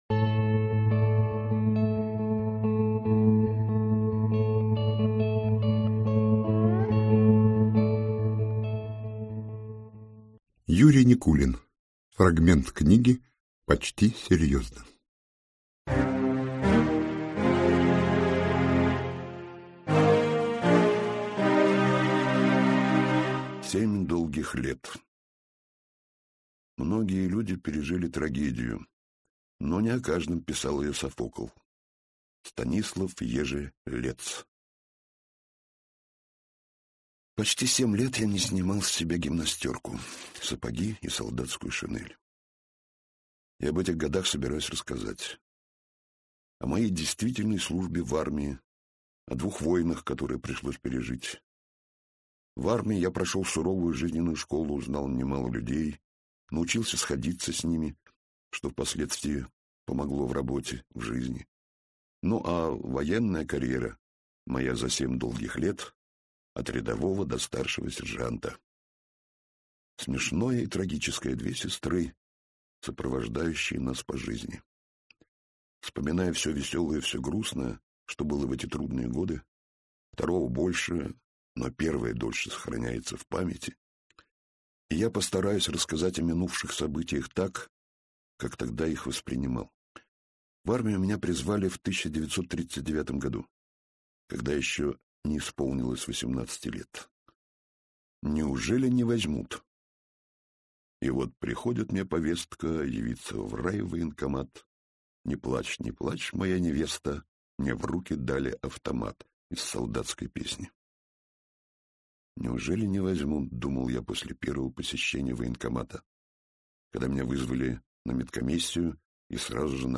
Аудиокнига Семь долгих лет | Библиотека аудиокниг